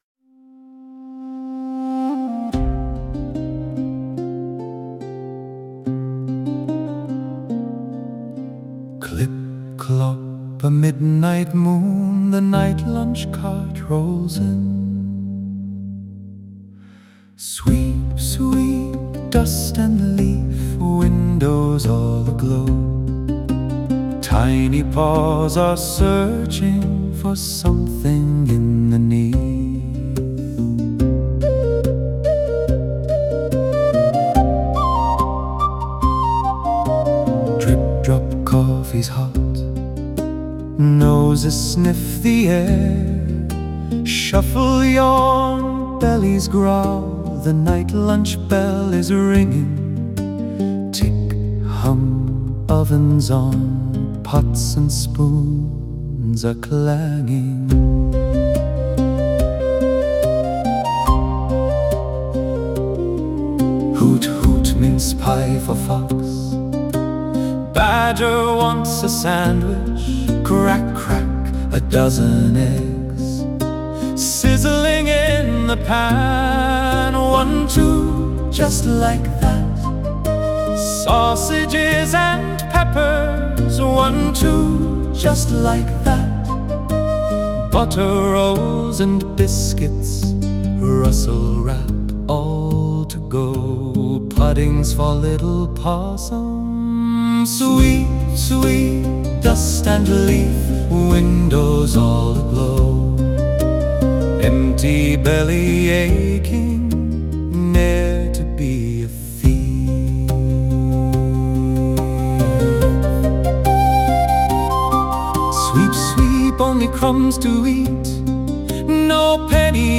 Medieval Folk Ballad